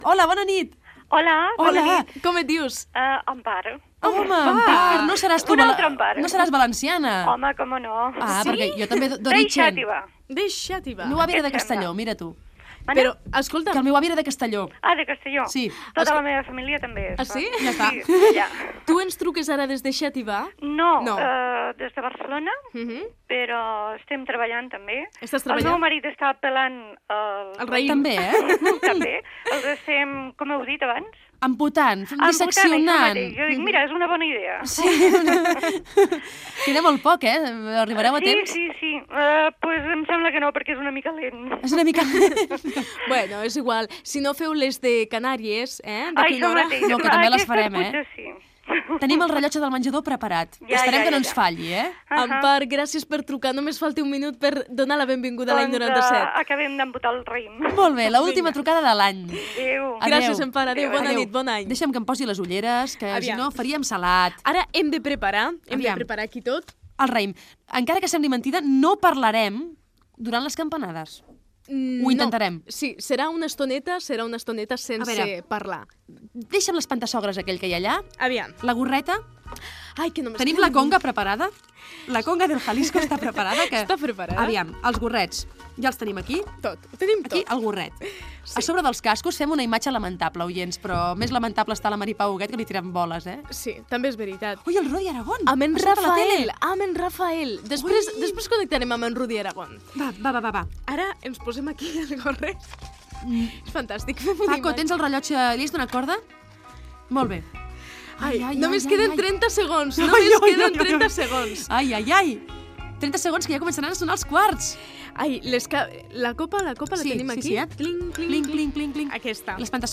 Participació telefònica d'una oient, preparatius,campanades de l'any 1997 i primera trucada des del barri de Sants
Entreteniment